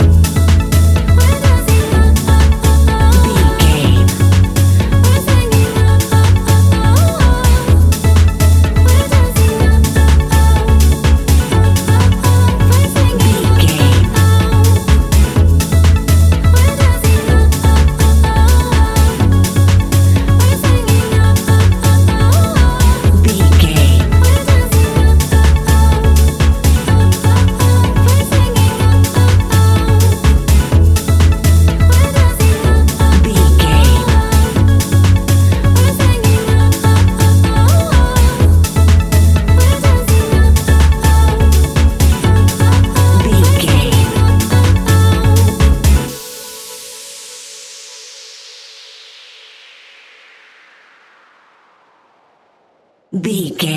Ionian/Major
F♯
house
electro dance
synths
techno
trance